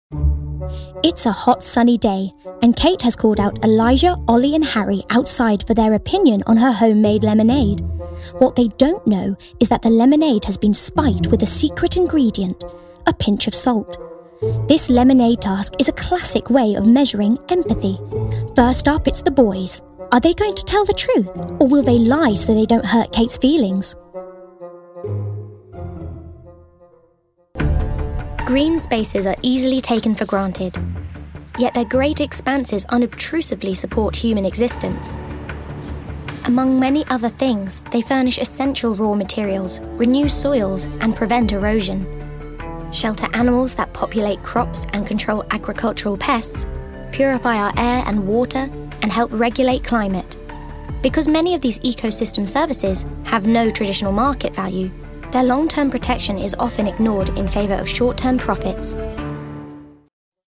Documentary
Standard English/RP, London/Cockney, American, Yorkshire, Irish
Actors/Actresses, Corporate/Informative, Modern/Youthful/Contemporary, Natural/Fresh, Smooth/Soft-Sell, Quirky/Interesting/Unique, Character/Animation, Upbeat/Energy